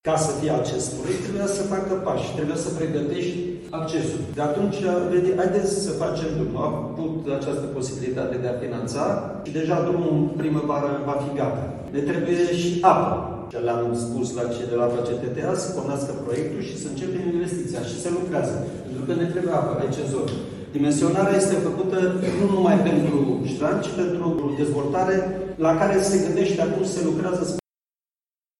Președintele Consiliului Județean Alba, Ion Dumitrel, a vorbit despre pașii care se fac și se vor face pentru ca investiția efectivă să poată începe.